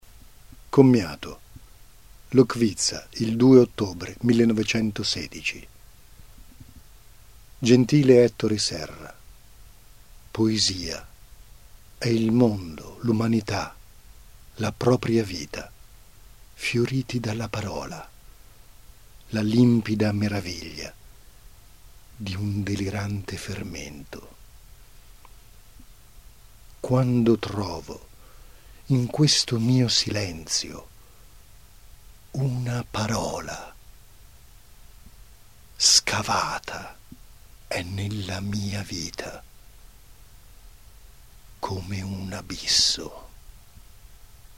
Recitazione